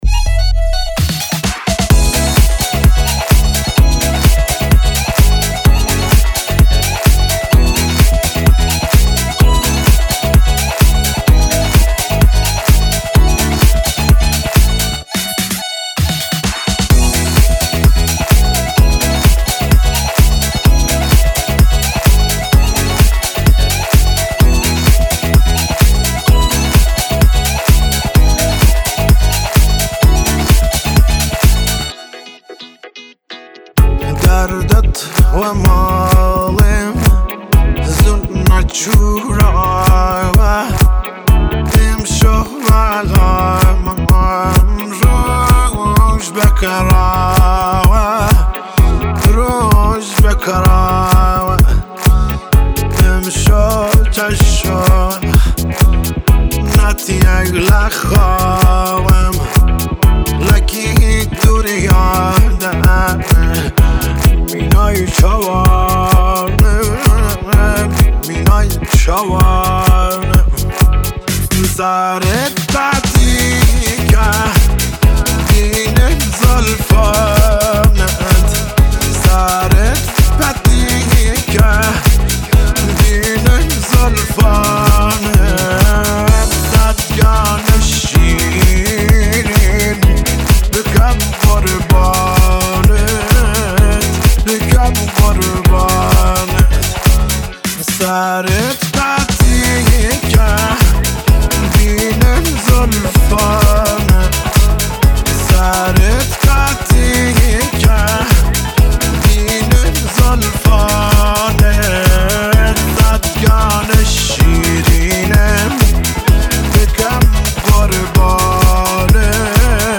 در میانه‌های قطعه است که آوازی از دور به گوش می‌رسد.